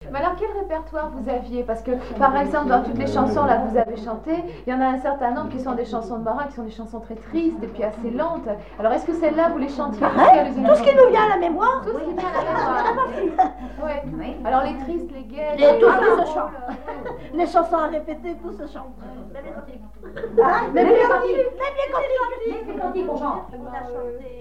collectif à la conserverie
Témoignage